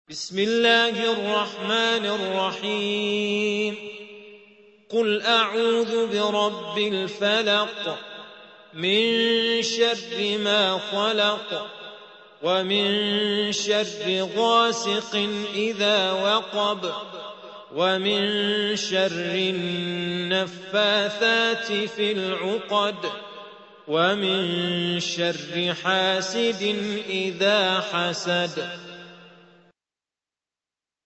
113. سورة الفلق / القارئ